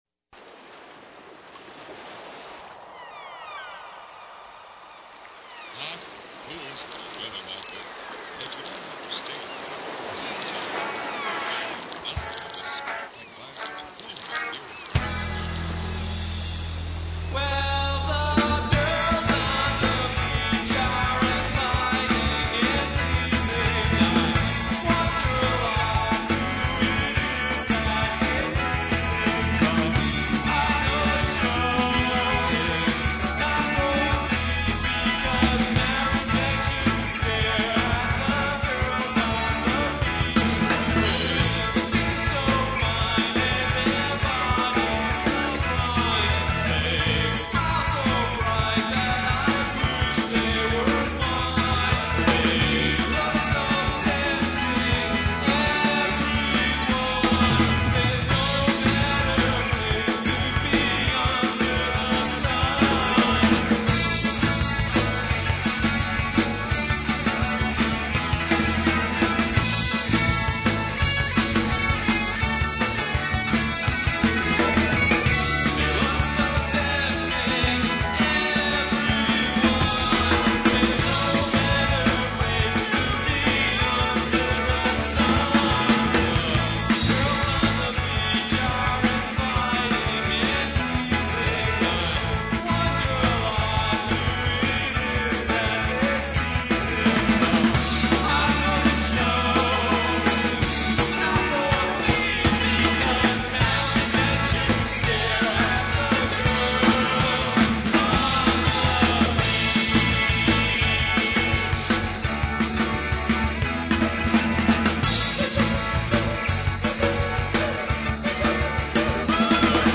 Keyboards, Vocals
Drums, Vocals
Bass, Vocals
Guitar, Lead Vocals